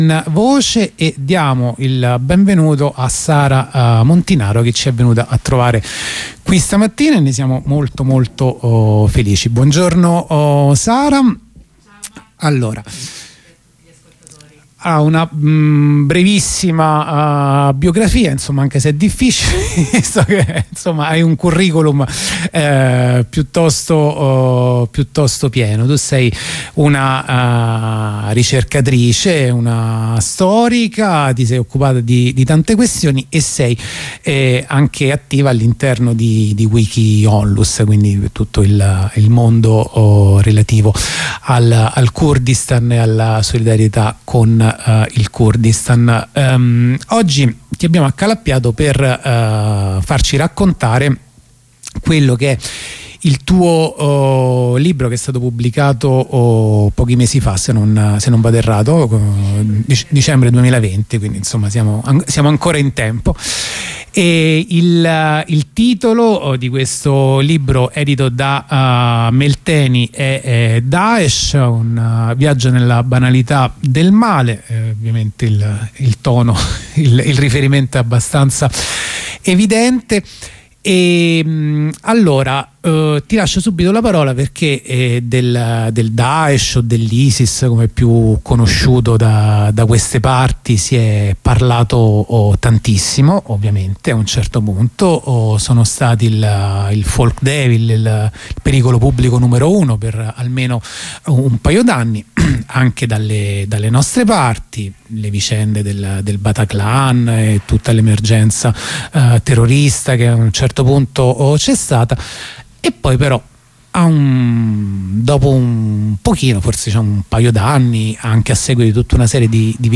Una compagna fa il punto della situazione